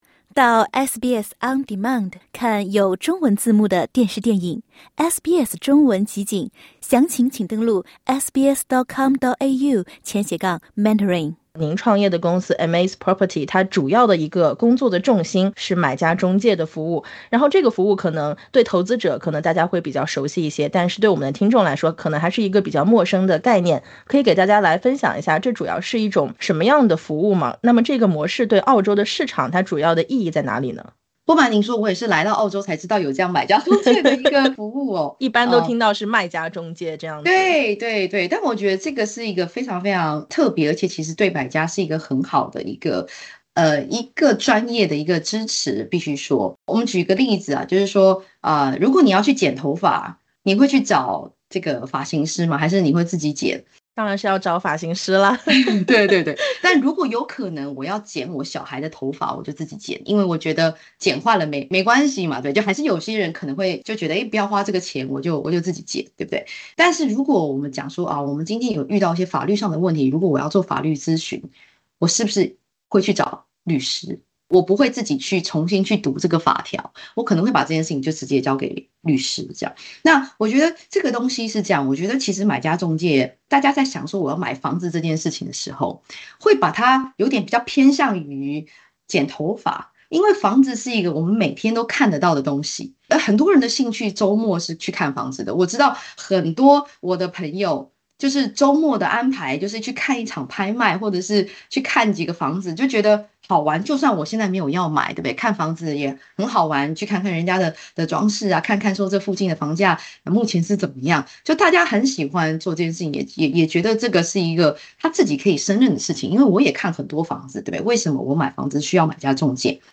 点击下方音频，收听完整采访： LISTEN TO 【闲话澳洲】什么是买家中介？